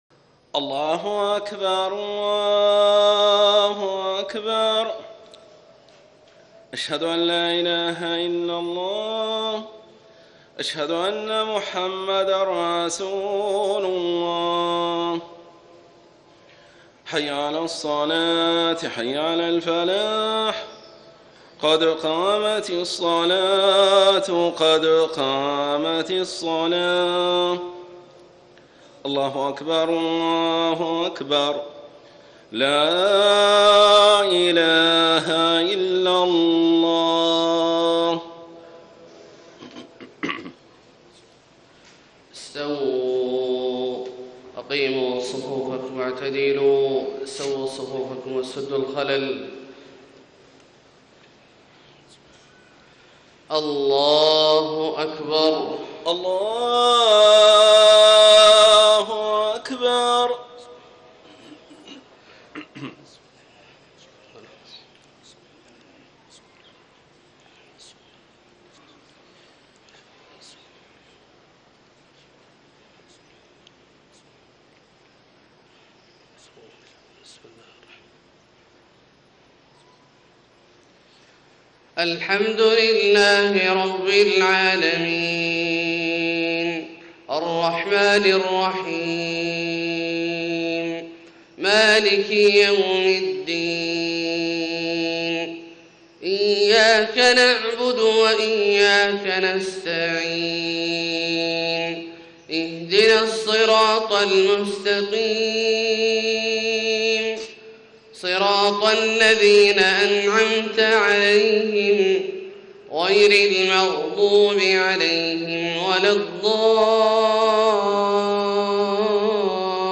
صلاة الفجر 7 - 7 - 1435هـ آخر سورة آل عمران > 1435 🕋 > الفروض - تلاوات الحرمين